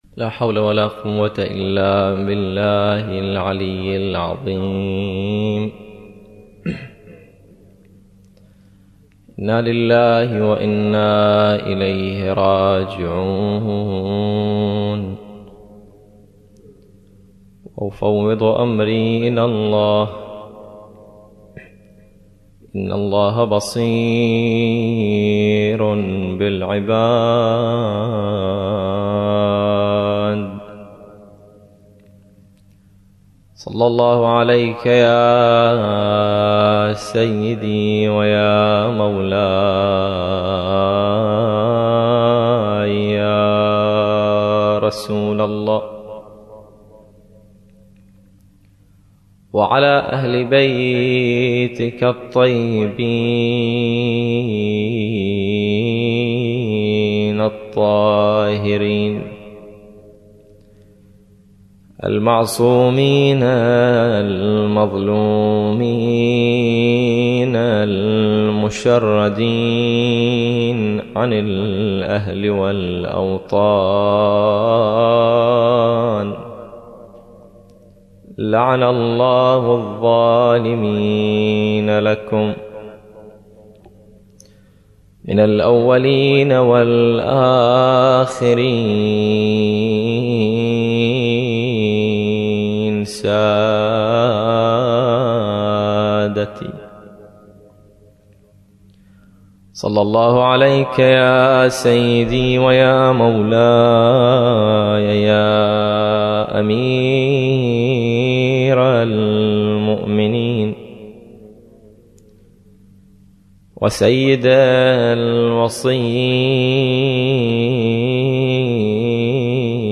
يوم وفاة امير المؤمنين علي بماتم النعيم الغربي 1433هـ - 2012